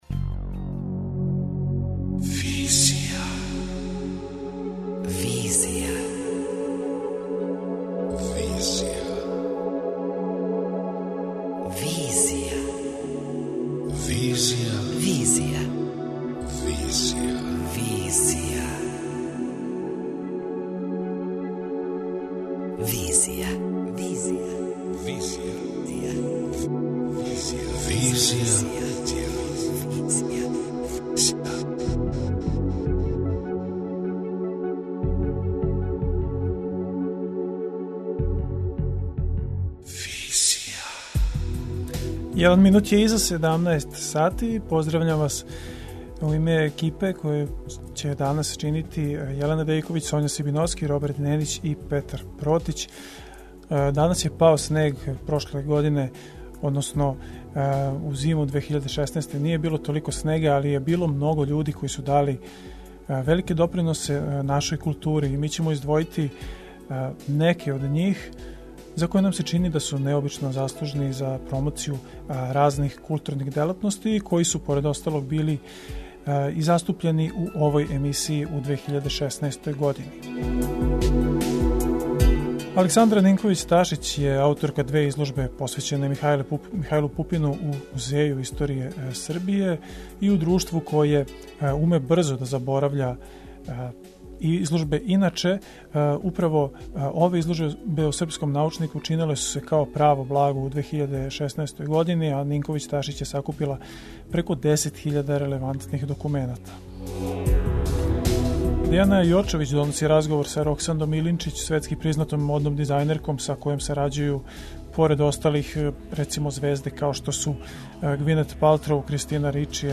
преузми : 27.69 MB Визија Autor: Београд 202 Социо-културолошки магазин, који прати савремене друштвене феномене.